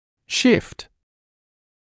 [ʃɪft][шифт]смещаться, сдвигаться; смещение, сдвиг